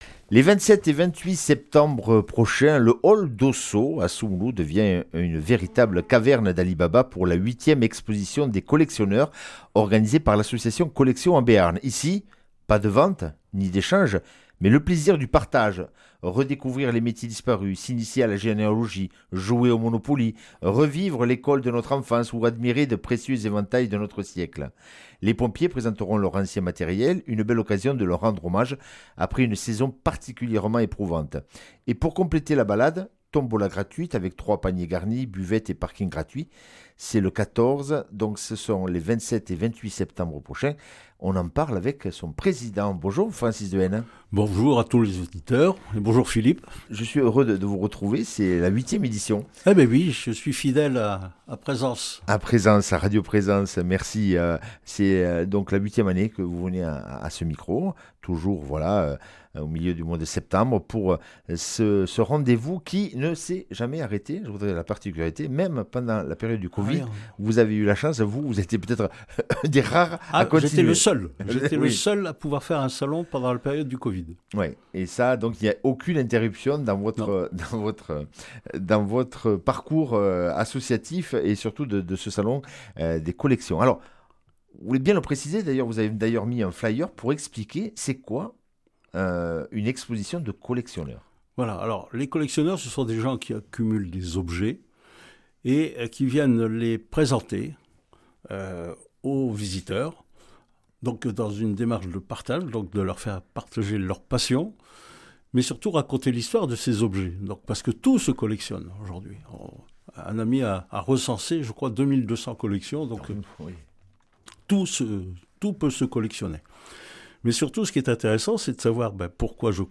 Interview et reportage du 15 sept.